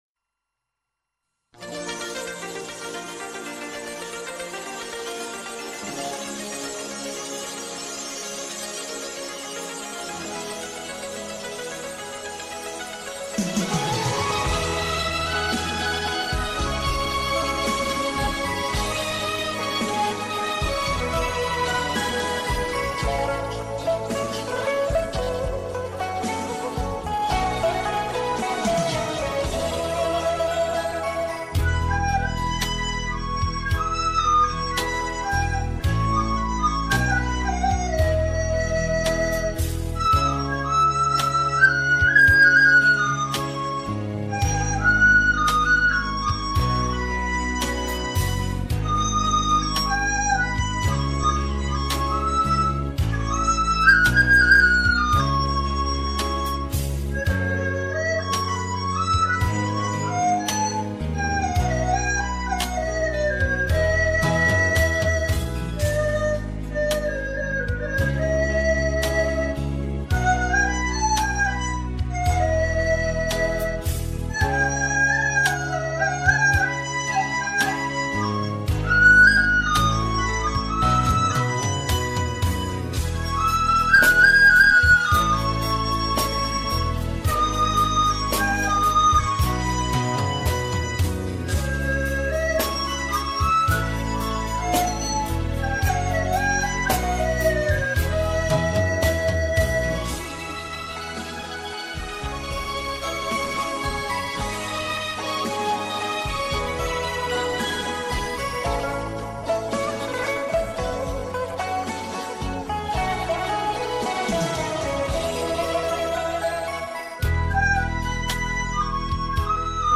清脆动人笛声令人沉醉，祝父亲节快乐！
笛声婉转悠扬 沁人心脾！